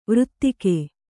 ♪ vřttike